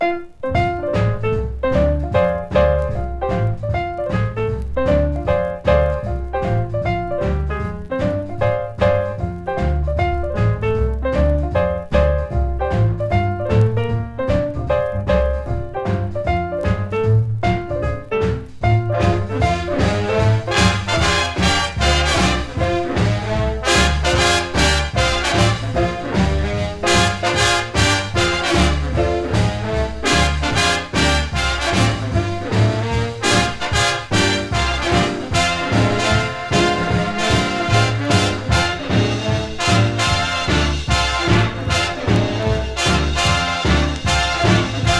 どれもギラギラとした豪快かつダンサブルなナンバーがたっぷりの構成が嬉しい1枚です。
Jazz, Blues, Jump Blues, Swing　USA　12inchレコード　33rpm　Mono